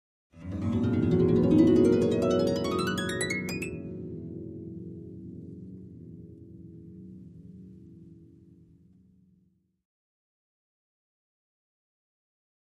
Harp, Ascending Gliss, Type 4